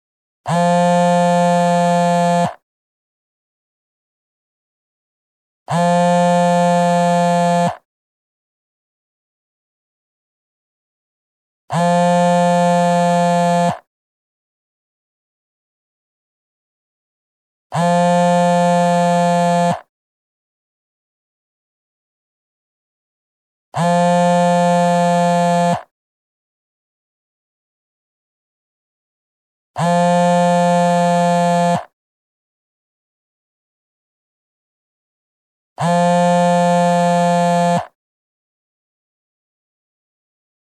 Вибрация телефона:
vibracija-telefona.mp3